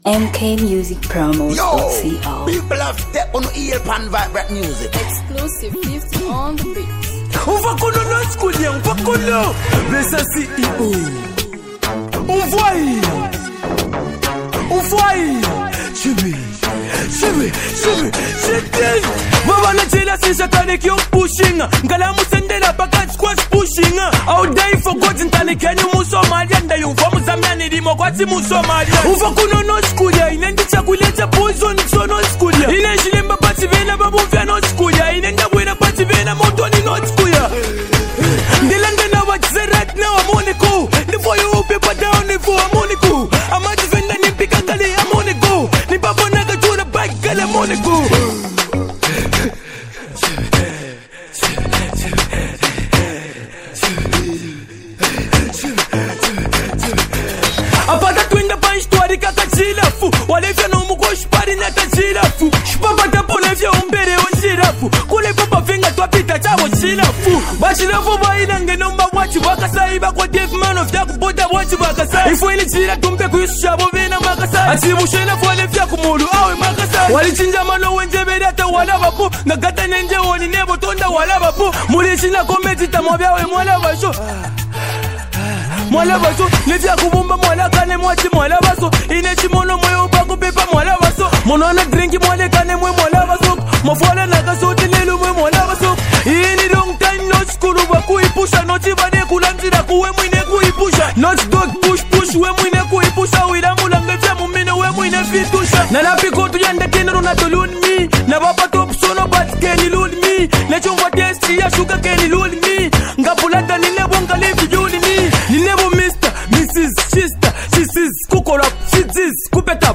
Zambian Hip-Hop 2026
This is not a hype track — it’s a thinking record.